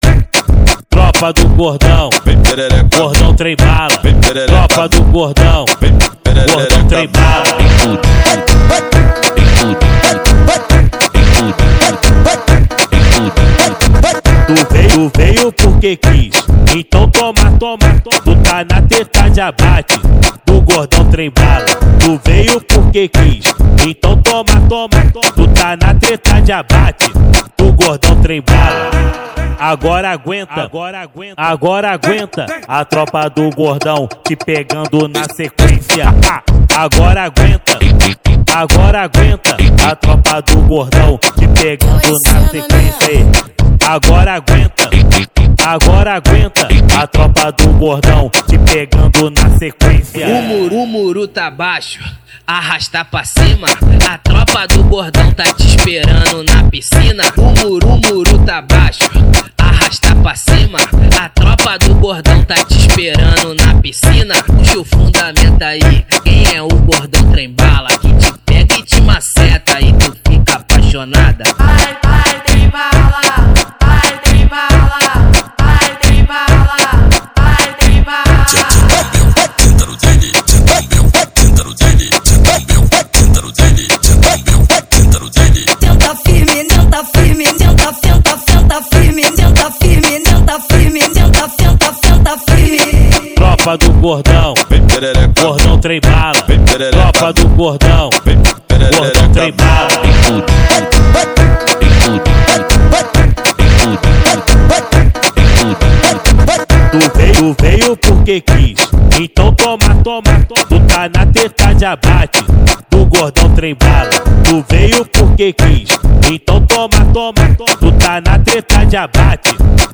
2024-06-15 20:10:36 Gênero: MPB Views